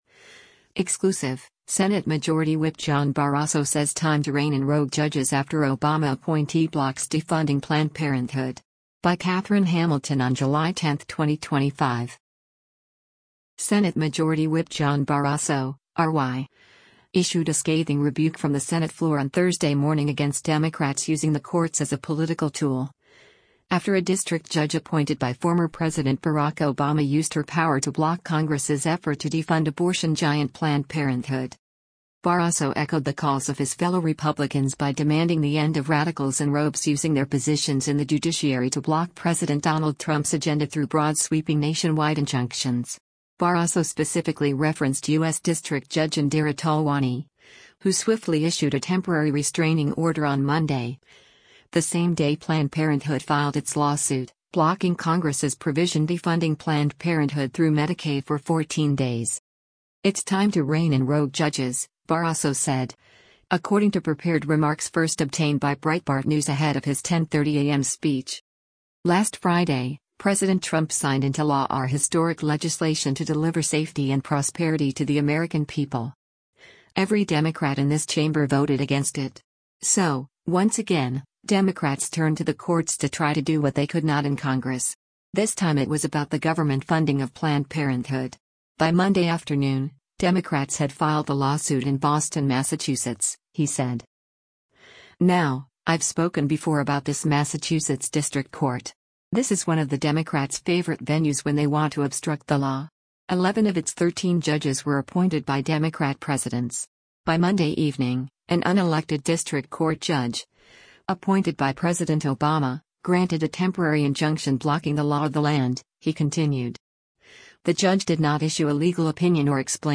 Senate Majority Whip John Barrasso (R-WY) issued a scathing rebuke from the Senate floor on Thursday morning against Democrats “using the courts as a political tool,” after a district judge appointed by former President Barack Obama used her power to block Congress’s effort to defund abortion giant Planned Parenthood.